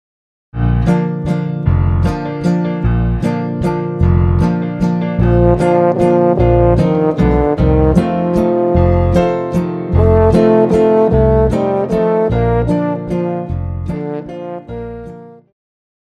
Classical
French Horn
Band
Traditional (Folk),Classical Music,Classical Rearrangement
Instrumental
Only backing